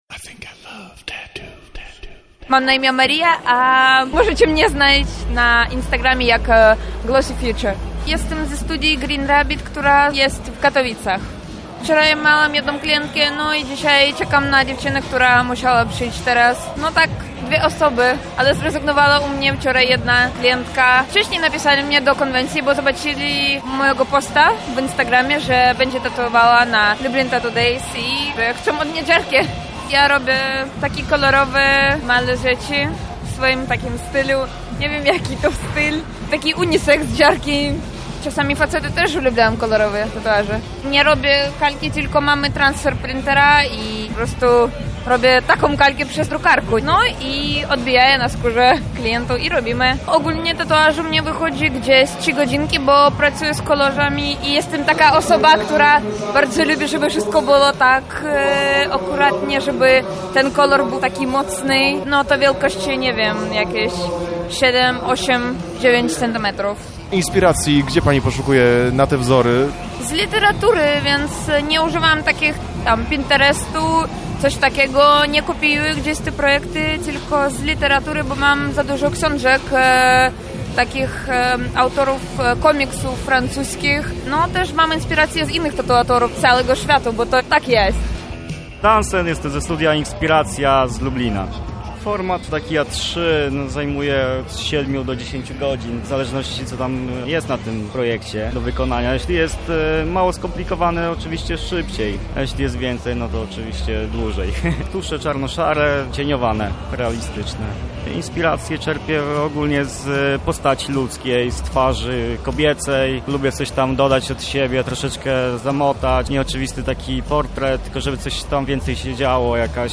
W hali C Targów Lublin tatuatorzy przez 2 dni pracowali w pocie czoła.
Na miejscu był nasz reporter